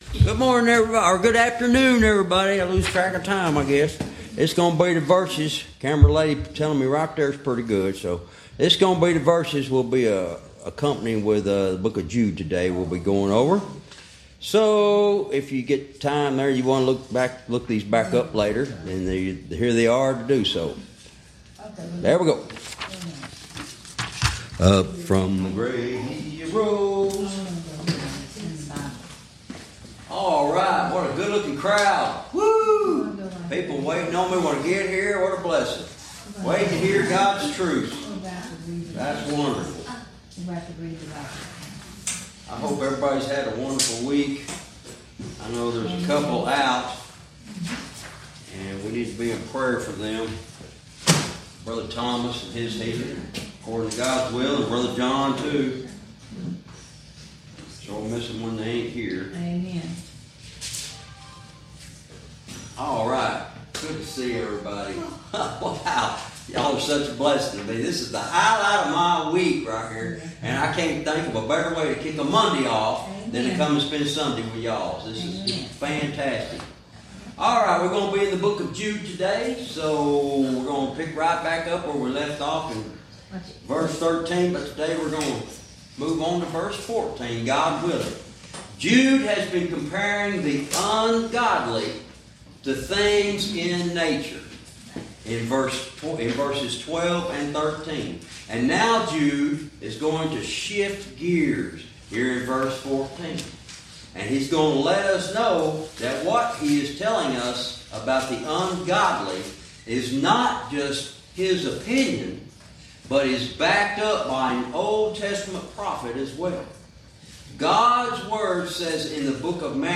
Verse by verse teaching - Jude Lesson 57 Verse 14